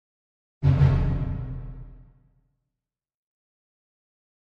Drum Deep Symphonic - Orchestra Drum Double Hit - Higher